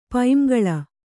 ♪ paiŋgaḷa